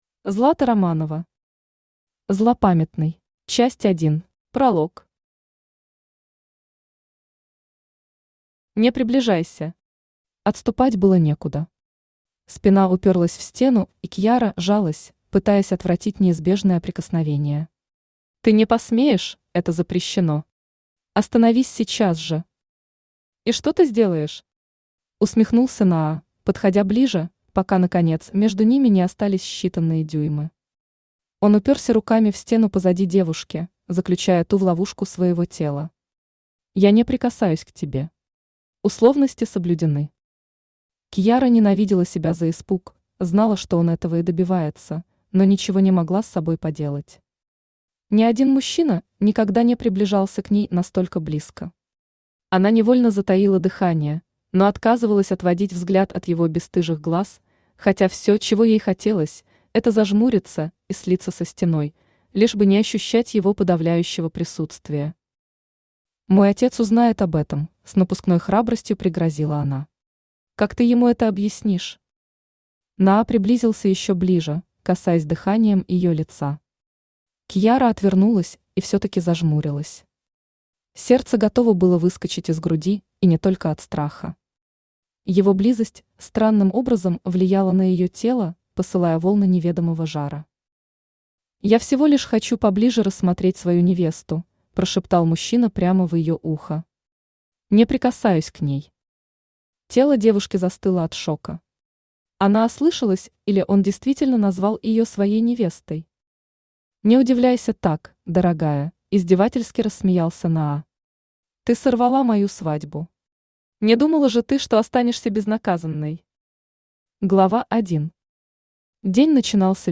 Аудиокнига Злопамятный | Библиотека аудиокниг
Aудиокнига Злопамятный Автор Злата Романова Читает аудиокнигу Авточтец ЛитРес.